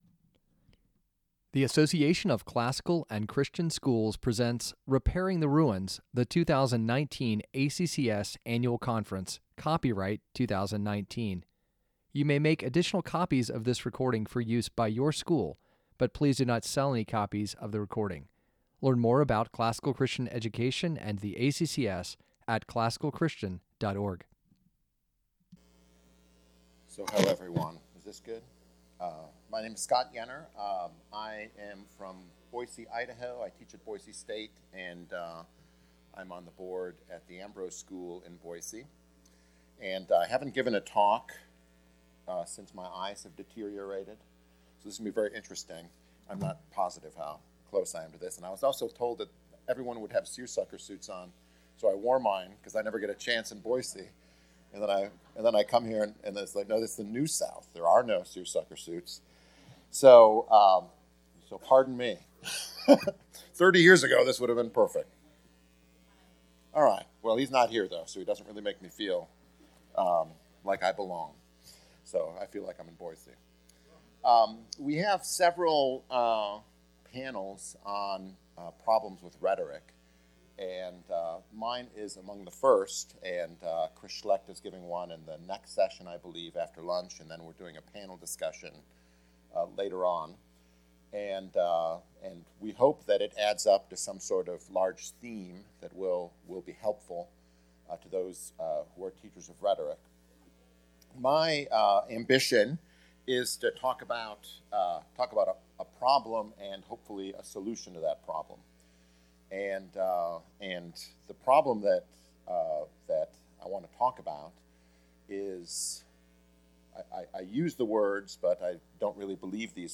2019 Workshop Talk | 53:26 | 7-12, Rhetoric & Composition